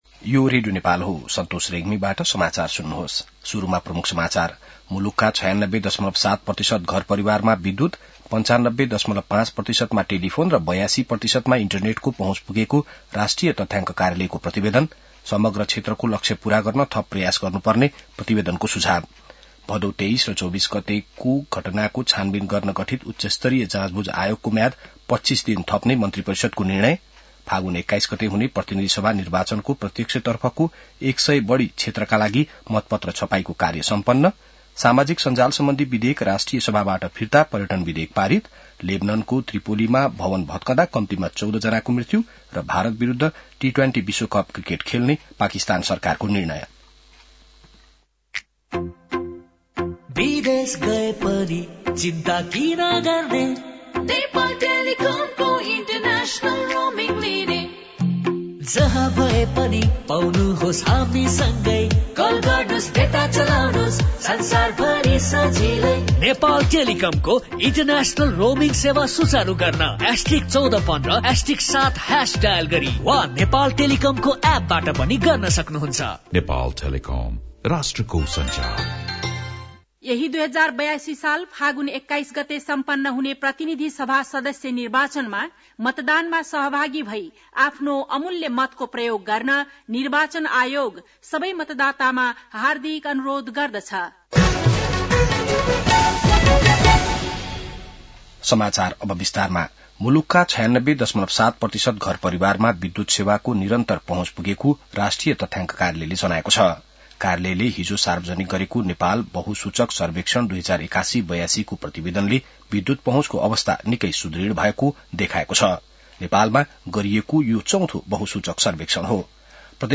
An online outlet of Nepal's national radio broadcaster
बिहान ७ बजेको नेपाली समाचार : २७ माघ , २०८२